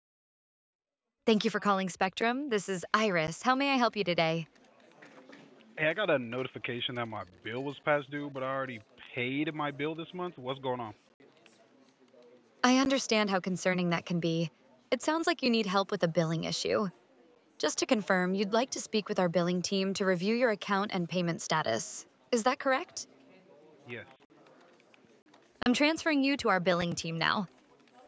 Hear how AI answers real calls
• Natural, conversational voice